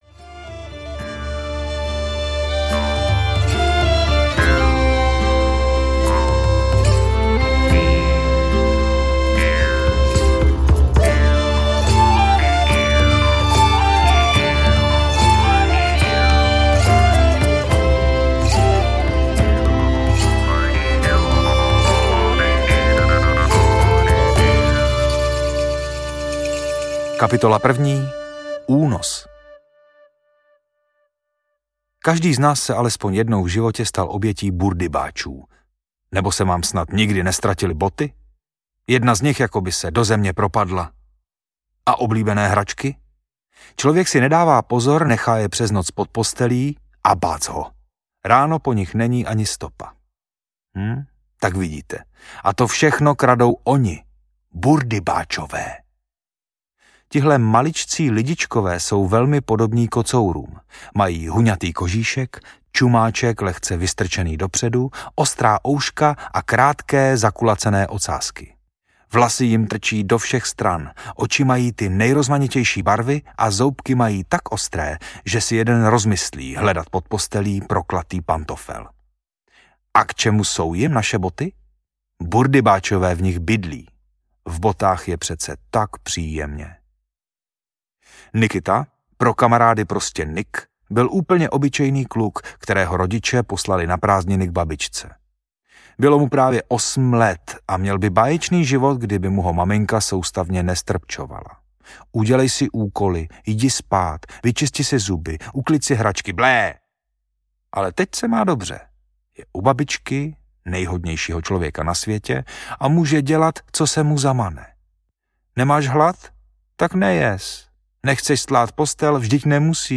Burdybáč audiokniha
Ukázka z knihy
burdybac-audiokniha